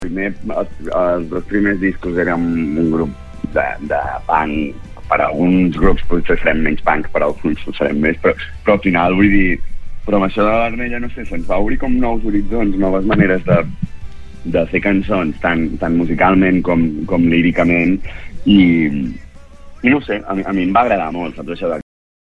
Entrevistes SupermatíMúsica